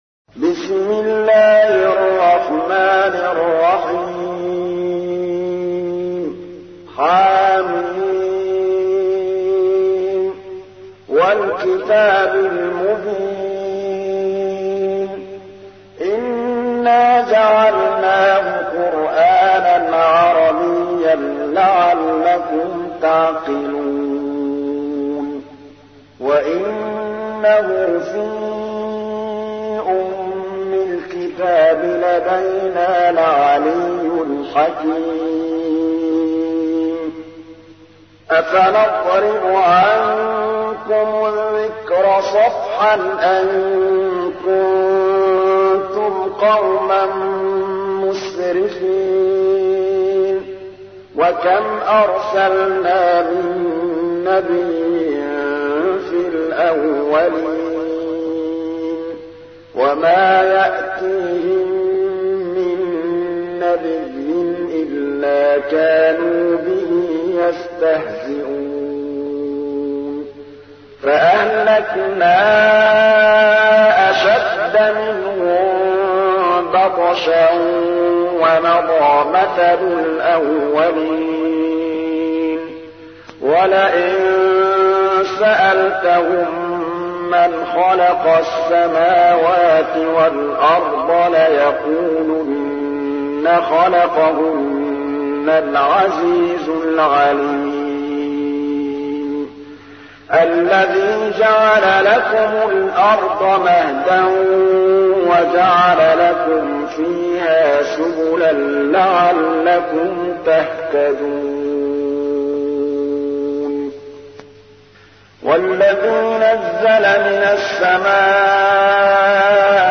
تحميل : 43. سورة الزخرف / القارئ محمود الطبلاوي / القرآن الكريم / موقع يا حسين